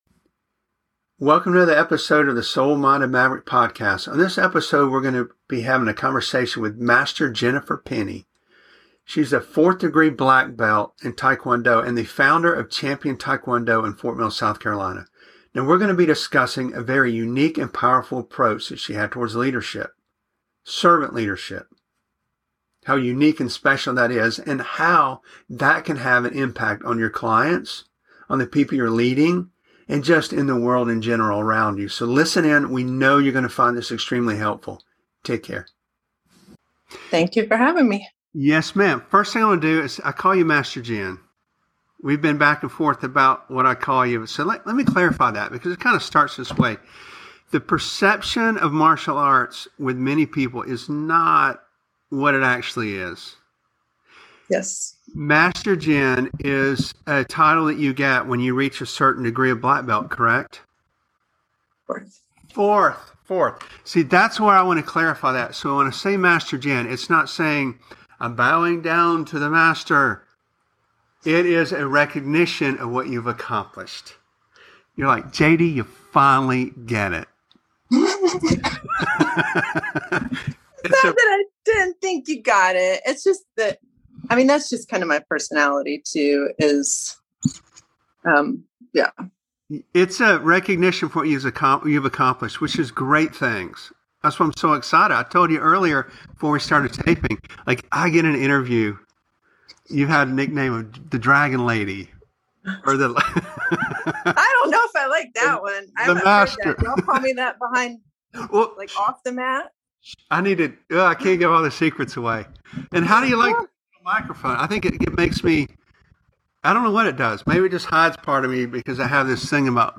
This is a behind the scenes conversation of a very effective style of martial arts.